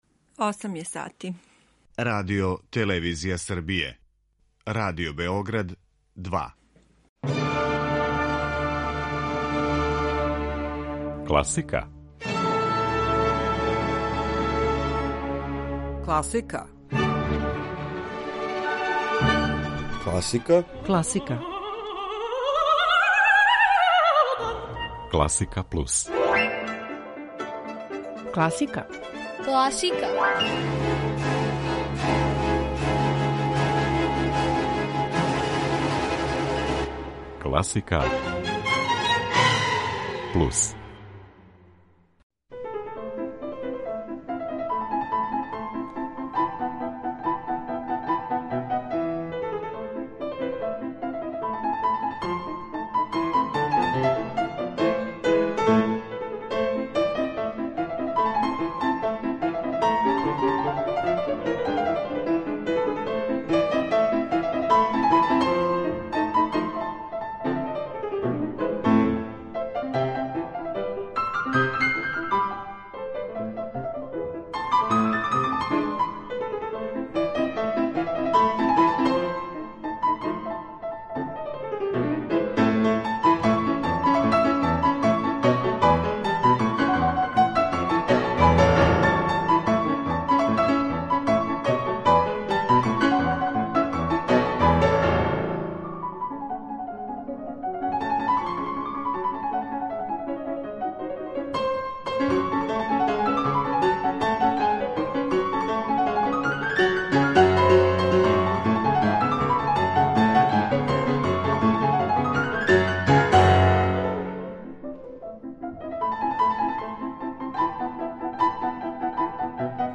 Међу њима су пијанисти Фазил Сај и Кит Џерет, кларинетиста Бени Гудман, мандолиниста Крис Тајл, виолончелиста Јо Јо Ма и други.